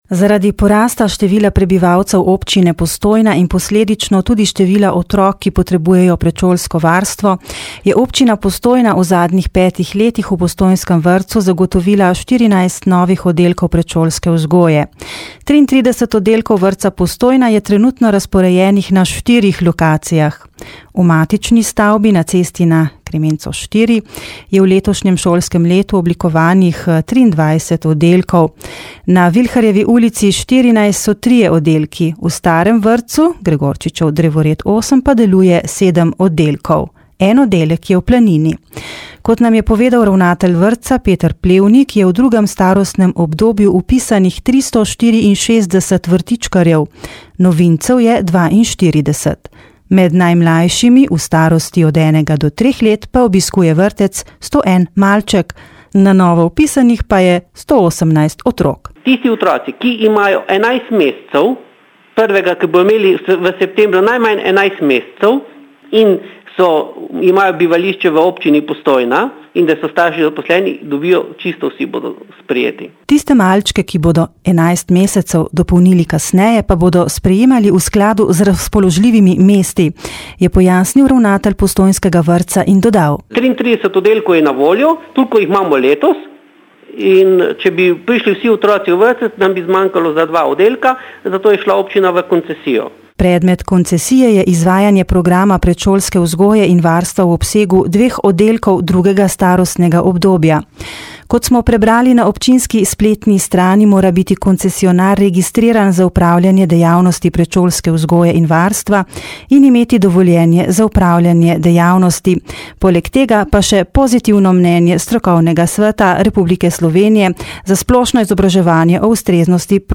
• novice radio94 r94